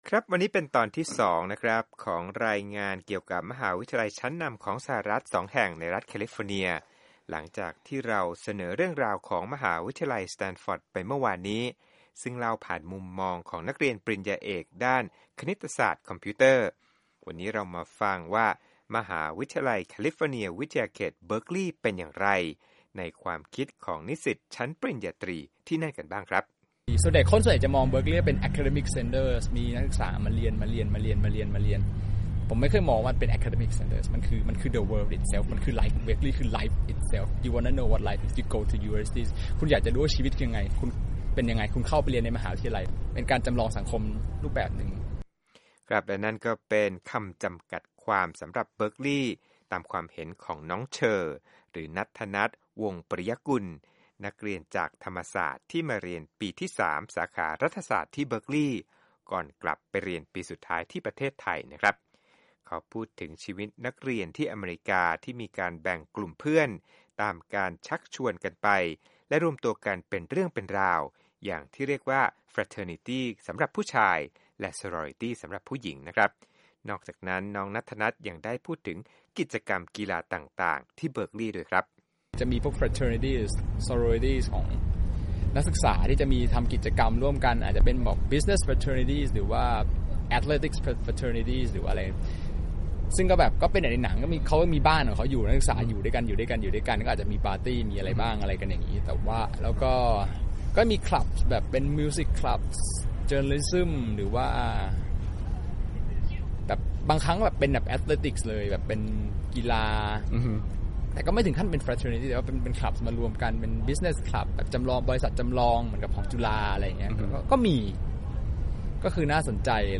Education Berkeley Interview